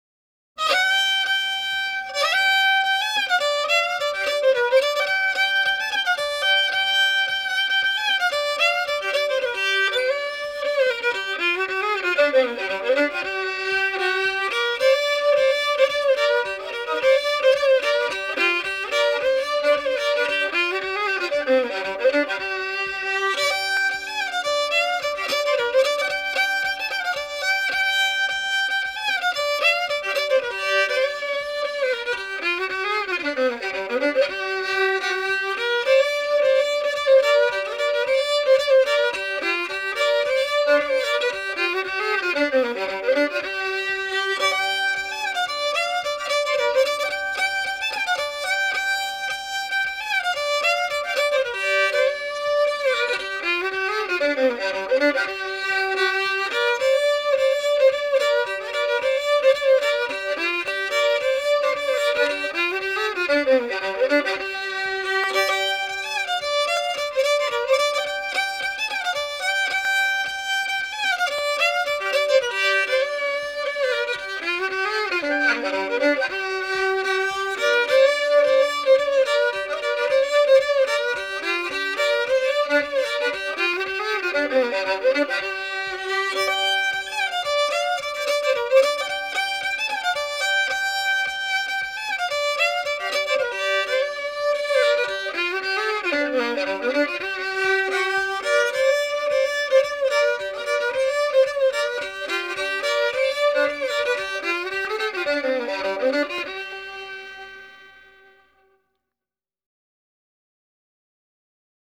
Cross-tuned and as crooked as Noble County two-lane.